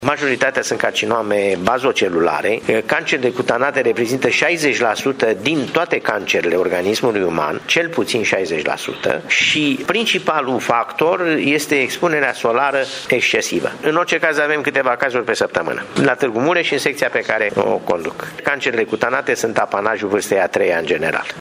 a declarat azi, cu ocazia implinirii a 30 de ani de la înfiintarea Clinicii de Chirurgie Plastica